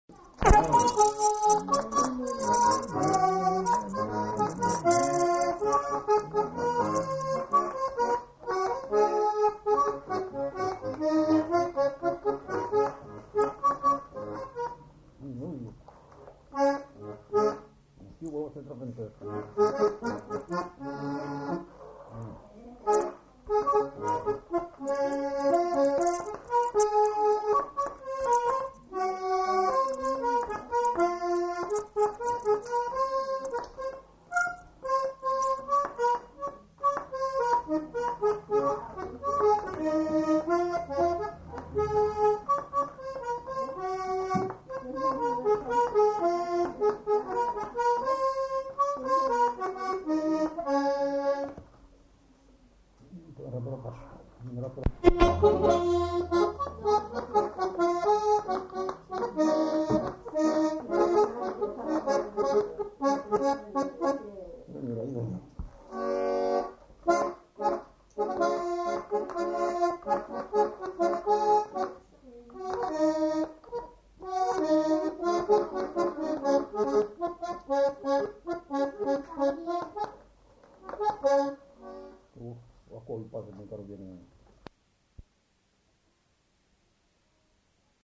Aire culturelle : Savès
Lieu : [sans lieu] ; Gers
Genre : morceau instrumental
Instrument de musique : accordéon diatonique
Danse : quadrille